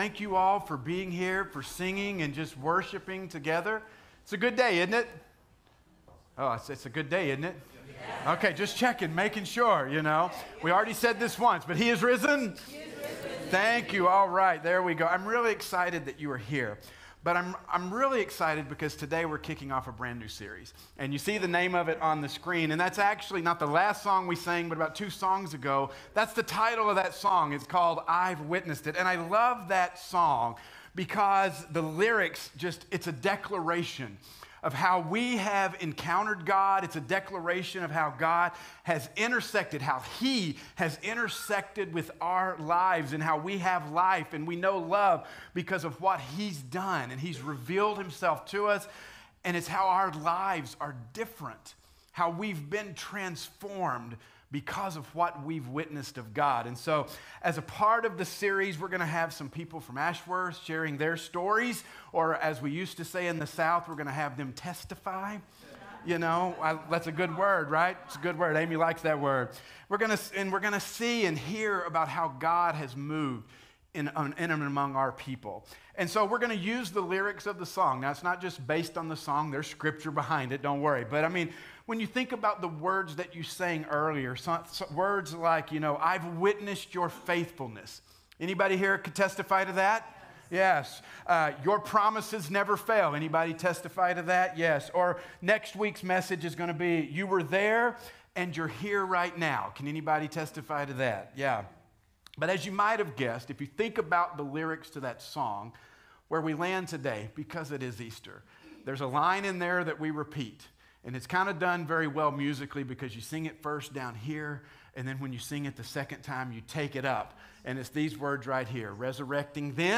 The resurrection of Jesus puts into motion the possibility of resurrection for His people. This Easter Sunday, we’re launching a new sermon series about the transformation Jesus brings about and the testimonies of His people.